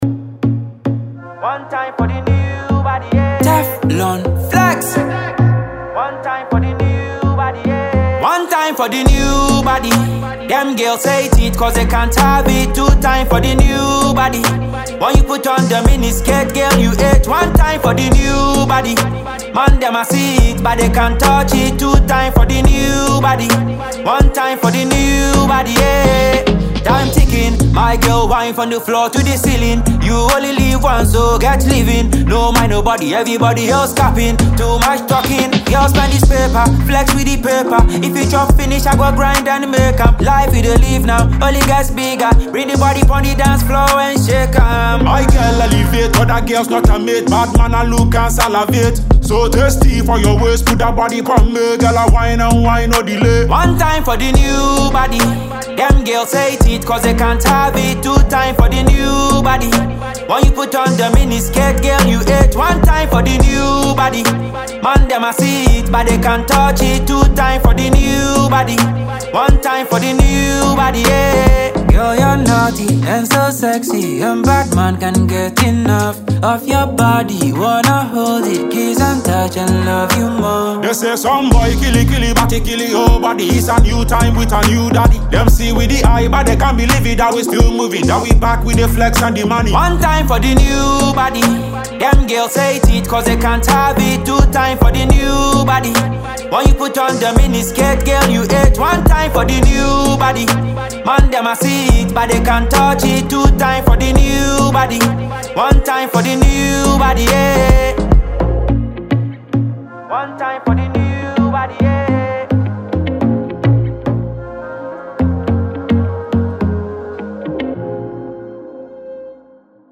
is a danceable tune for all